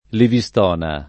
[ livi S t 0 na ]